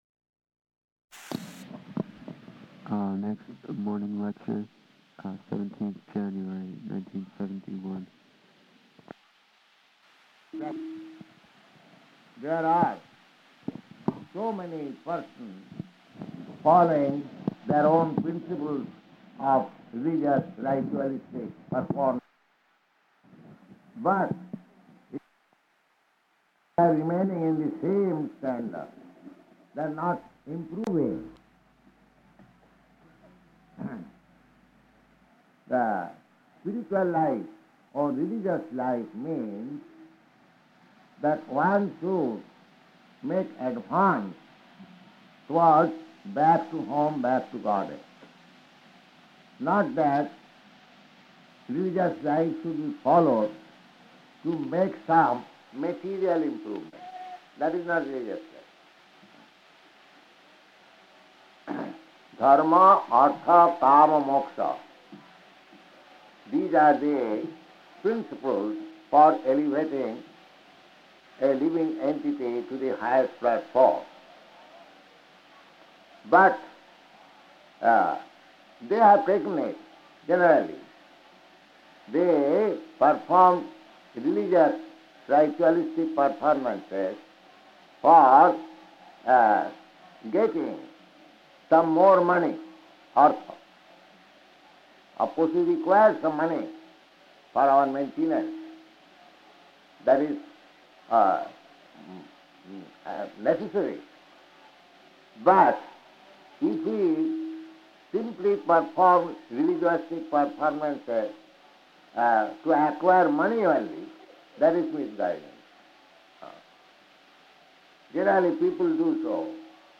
Śrīmad-Bhāgavatam 6.2.12–14 at Kumbha-melā --:-- --:-- Type: Srimad-Bhagavatam Dated: January 17th 1971 Location: Allahabad Audio file: 710117SB-ALLAHABAD.mp3 Devotee: [introduction on recording] Next morning lecture, 17th January, 1971.